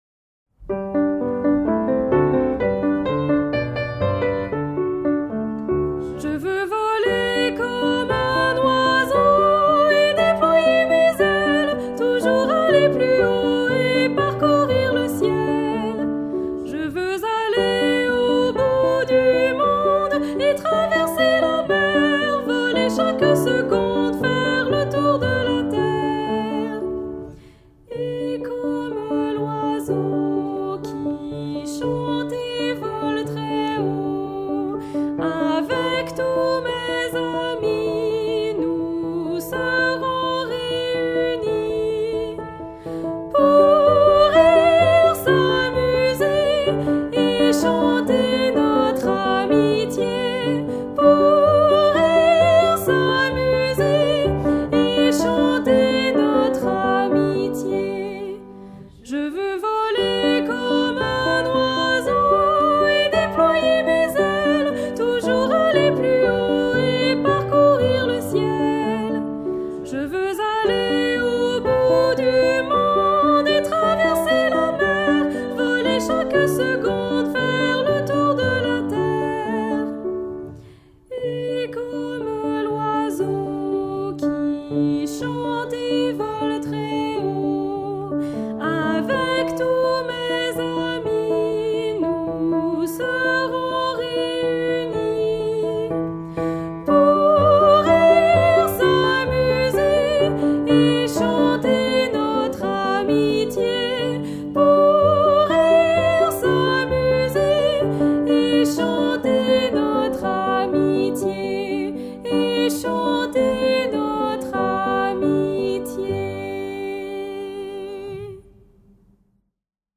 Audio voix 1